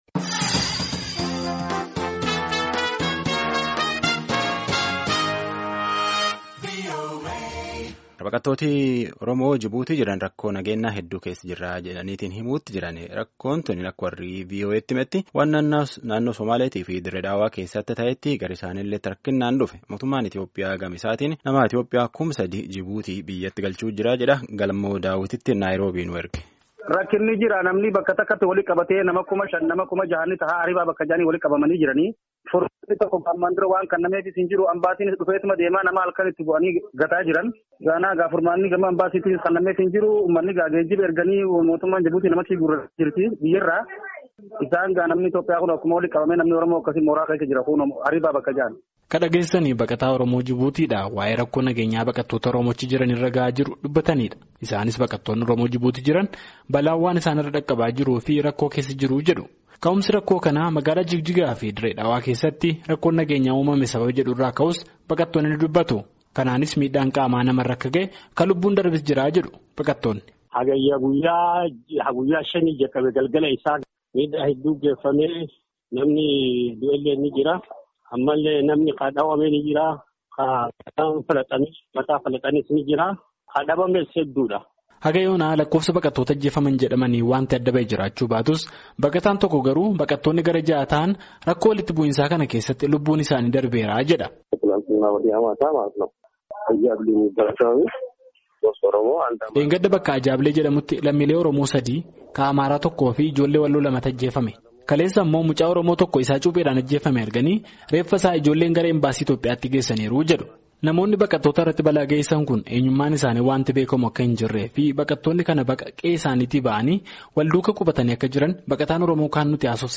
Baqattoonni Oromoo Jibuutii jiraatan rakkoo nageenyaan rakkataa jiraachuu dubbatan. Baqattoonni kunneen Raadiyoo VOA’f yoo dubbatan, jiraattonni biyyattii rakkoo nageenyaa Naannoo Somaalee fi Magaala Dirre Dhawaatti mulateen wal qabatee lammilee Oromoo Jibuutiin jiraatan irraan miidhaa geessisuu isaanii dubbatu.